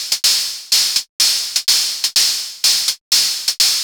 cch_percussion_loop_hatzz_125.wav